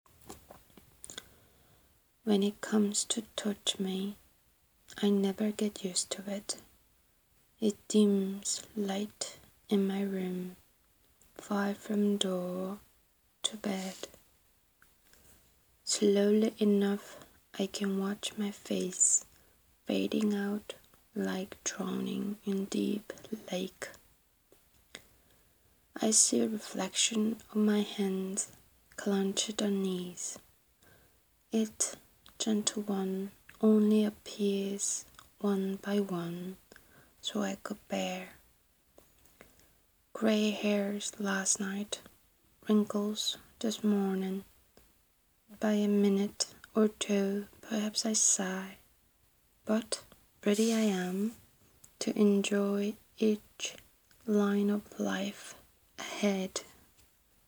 꼬리가 일곱 어제까지의 축제 영시집 PLAY 수정 전 낭독해봤습니다.